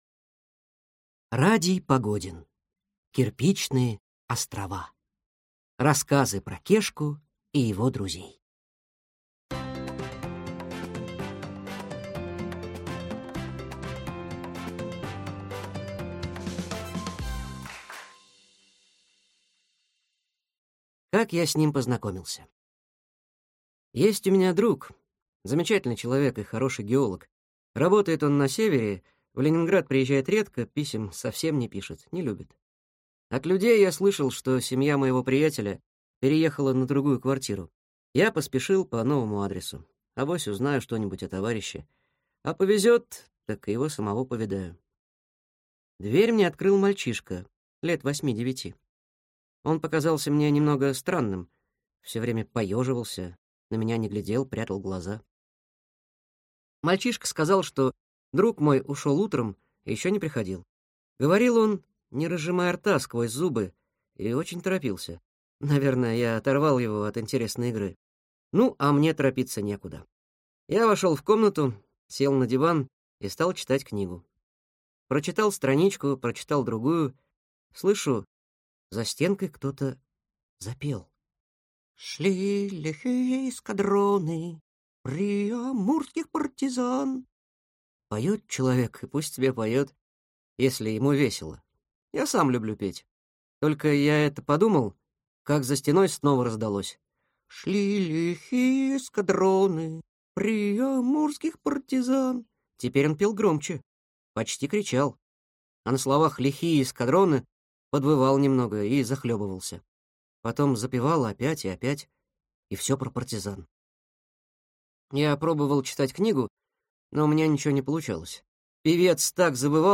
Аудиокнига Кирпичные острова. Рассказы про Кешку и его друзей | Библиотека аудиокниг